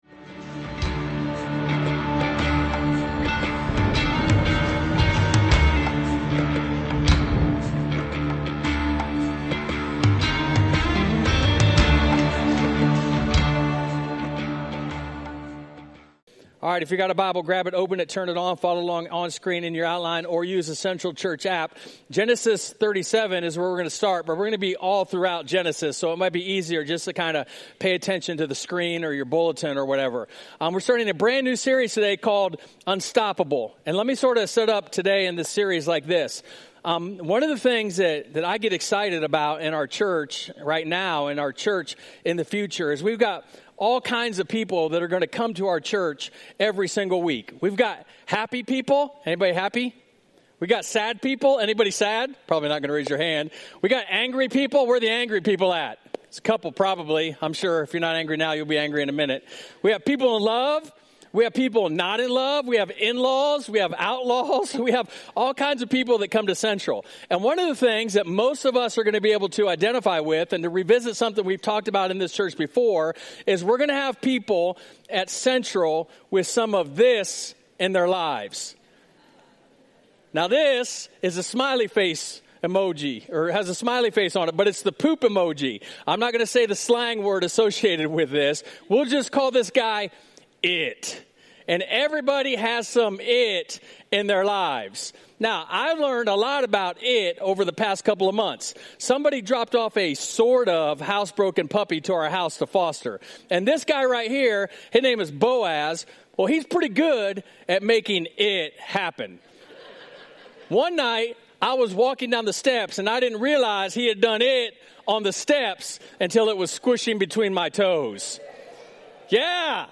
We launched our new sermon series, Unstoppable, with ""It" Happens" - inspired by the account in Genesis of Joseph.